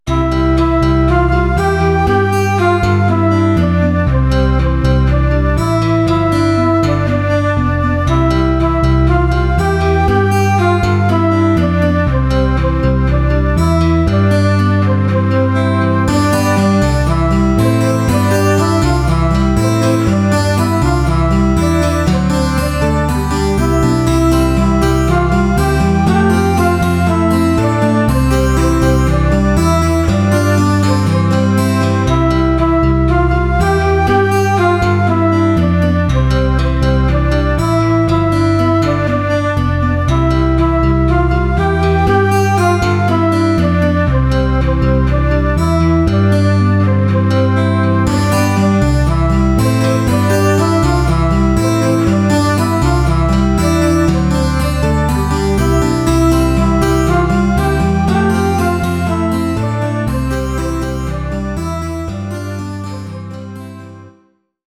GITARREN-AKKORDE